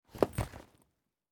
Звуки сумки, ранца
Сумка - Альтернативный вариант 2